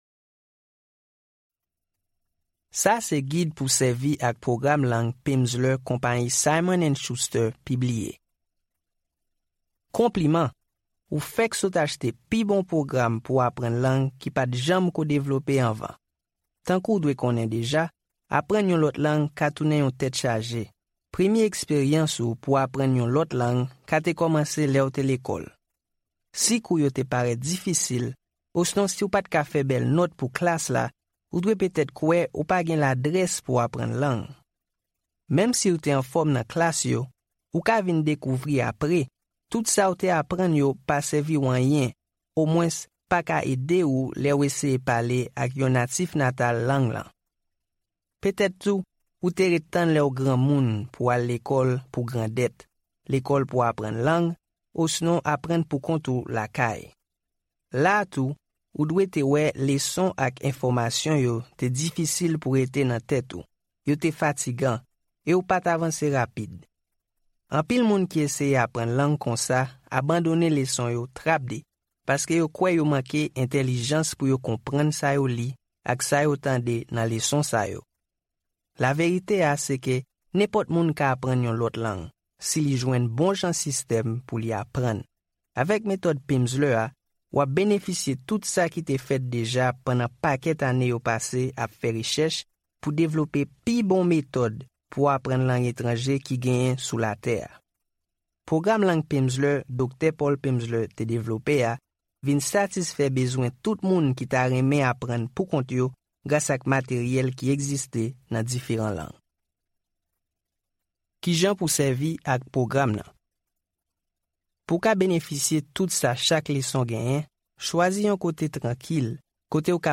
Unabridged — 2 hours, 47 minutes
Audiobook
Each lesson of English for Haitian Creole Speakers Phase 1, Units 1-5 provides 30 minutes of spoken language practice, with an introductory conversation, and new vocabulary and structures. Detailed instructions enable you to understand and participate in the conversation.